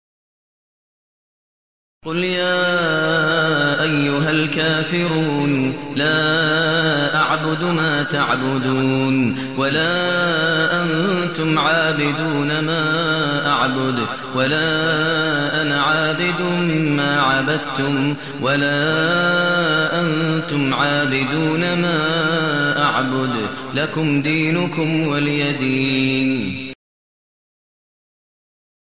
المكان: المسجد الحرام الشيخ: فضيلة الشيخ ماهر المعيقلي فضيلة الشيخ ماهر المعيقلي الكافرون